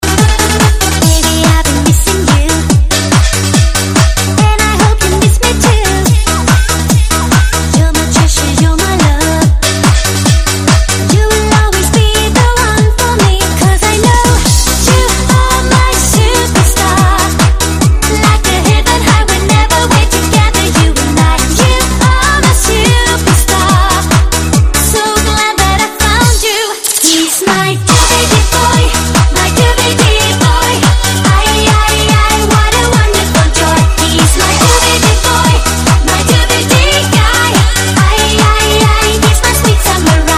分类: DJ铃声
DJ韩国舞曲